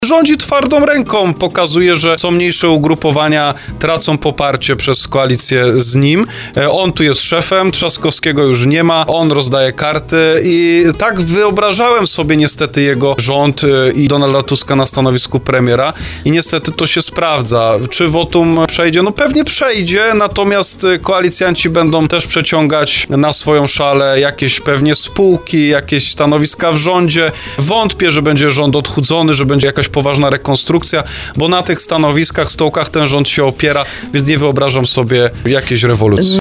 To on rozdaje karty – komentował w porannej rozmowie Słowo za Słowo poseł Norbert Kaczmarczyk z Prawa i Sprawiedliwości.